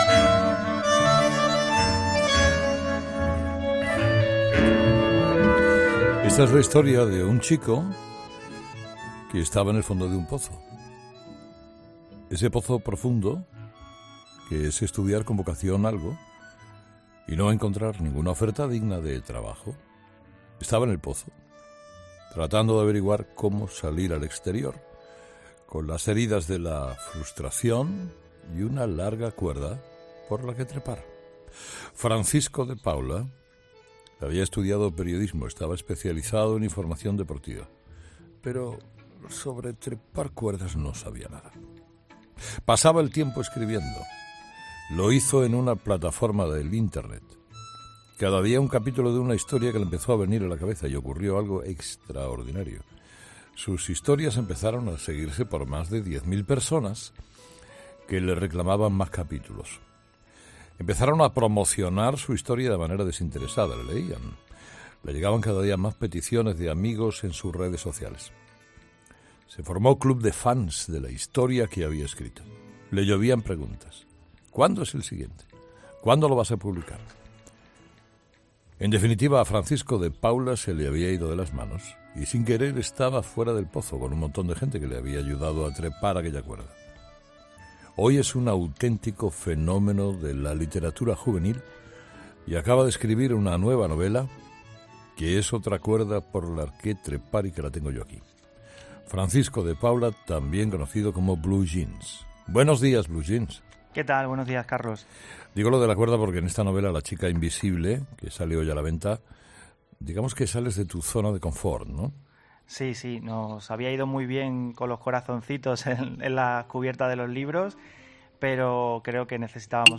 Escucha la entrevista a Blue Jeans en Herrera en COPE